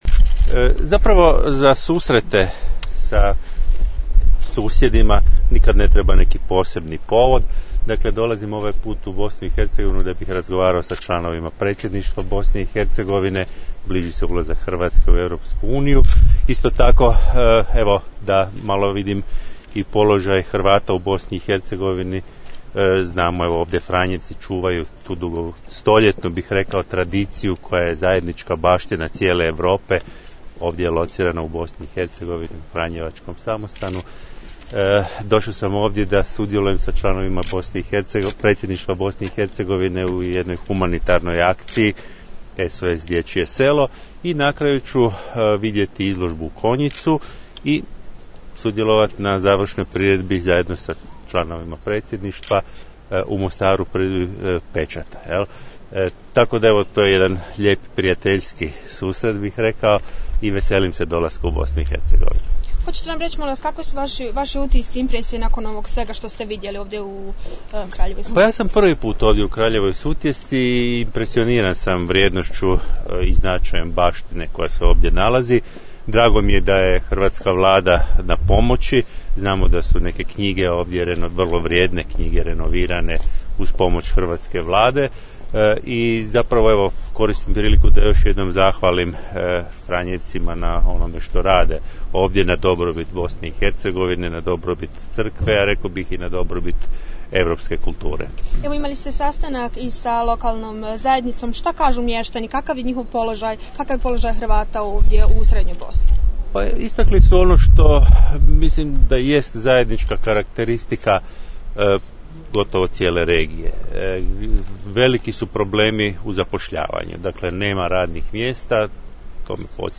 Izjava za medije dr Ive Josipovića